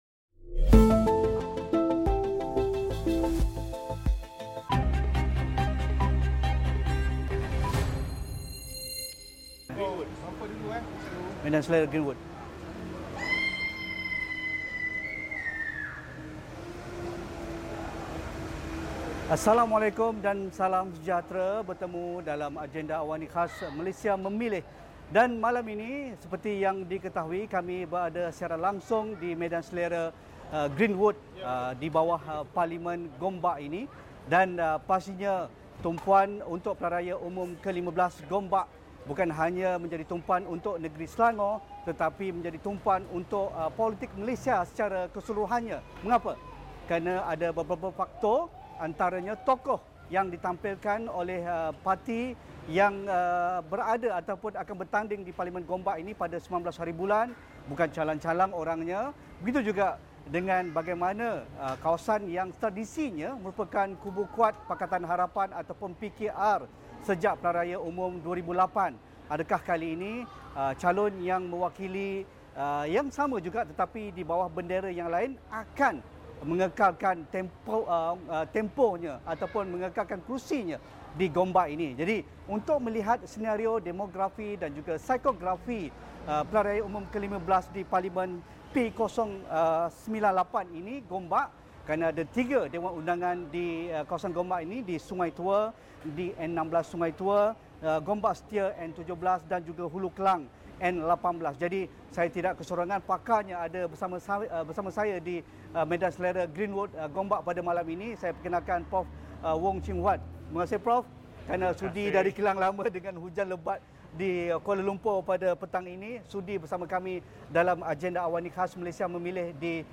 Temu bual khas bersama calon BN, PN dan PH dari Taman Greenwood, Gombak.